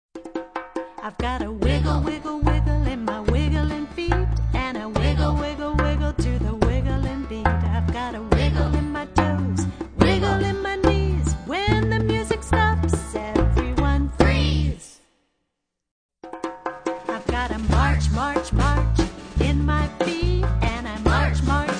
Action Song Lyrics